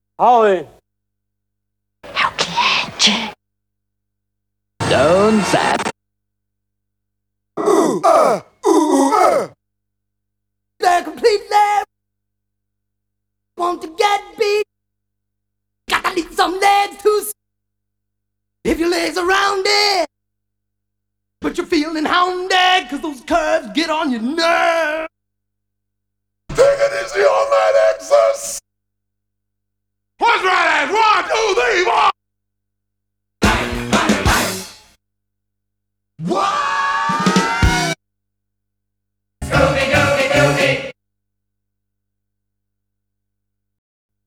35 Shouts!.wav